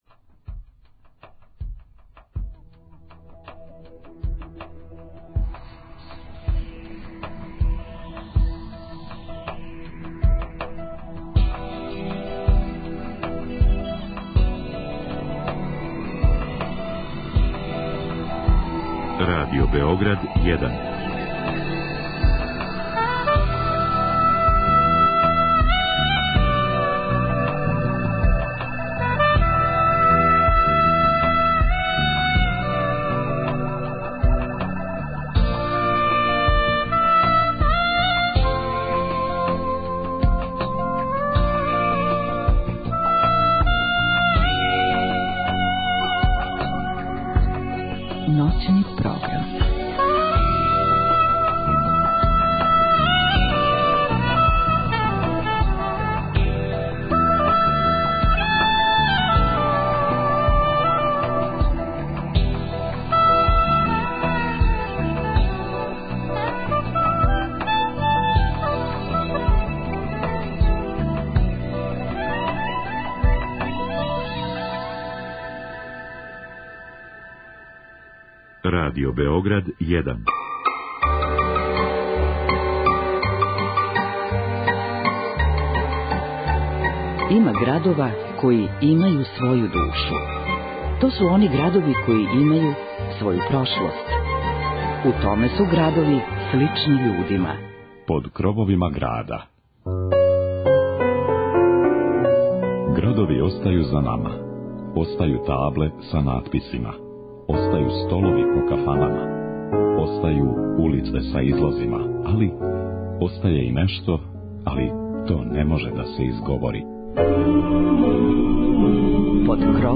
Ову ноћ обележиће кратке приче и занимљивости које су везане за Ниш. Музичку нит чиниће староградска и новоградска музика, романсе и изворна народна музика.